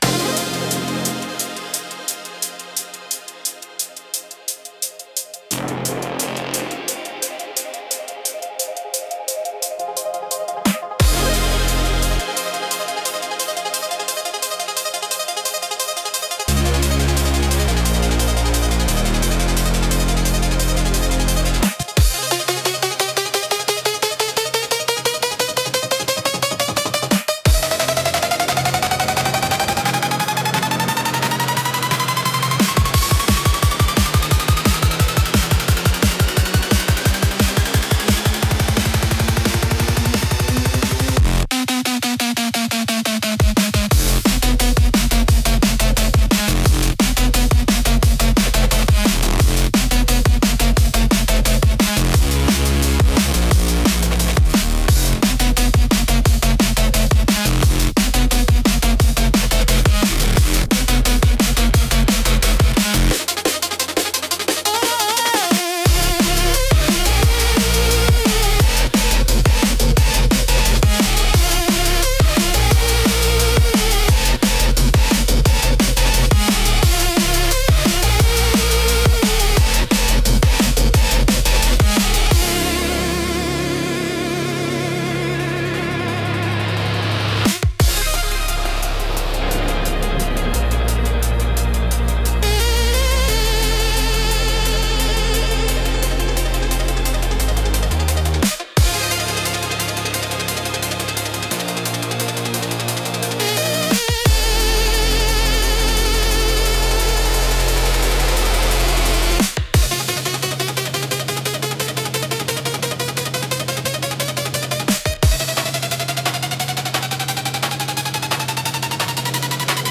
テンポの速さと複雑なリズムで、聴く人の集中力と興奮を引き上げる効果があります。エッジの効いたダイナミックなジャンルです。